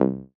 fnl/Assets/Extensions/Advanced_UI/Alerts_Notifications/Notifications/Uisynth5simpleC x2.wav at master
Uisynth5simpleC x2.wav